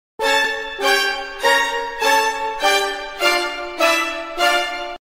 Goku Drip Meme Effect sound effects free download